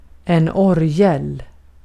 Ääntäminen
Ääntäminen Tuntematon aksentti: IPA: /'orˌjɛl/ Haettu sana löytyi näillä lähdekielillä: ruotsi Käännös Substantiivit 1. urut Artikkeli: en .